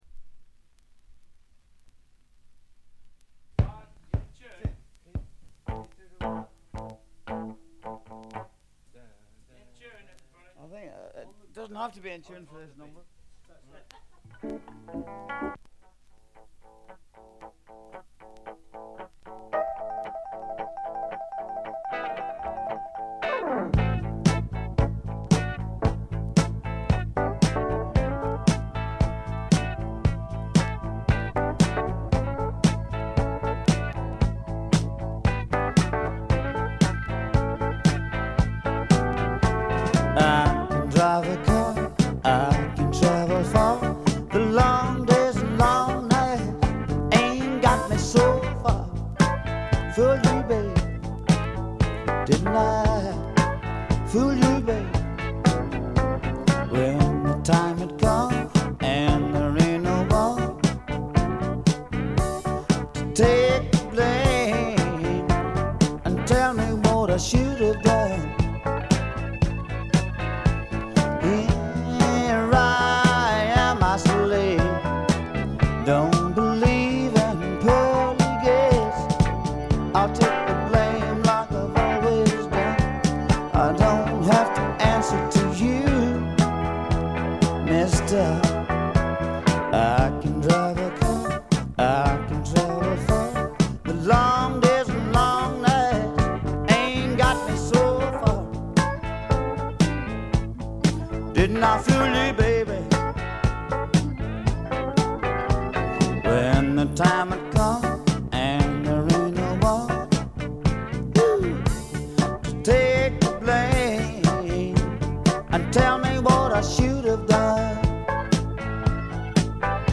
微細なノイズ感のみ。
ルーズでちょこっと調子っぱずれなあの愛すべきヴォーカルがまたよくて、本作の雰囲気を盛り上げています。
試聴曲は現品からの取り込み音源です。